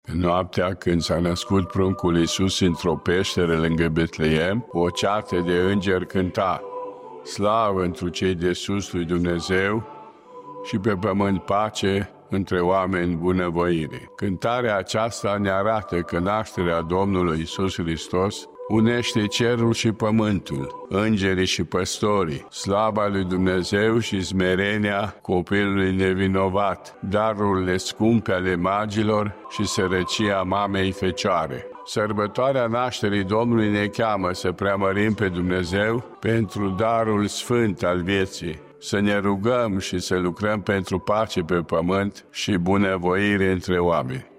Înaltul ierarh a adresat tuturor urări de pace şi sănătate: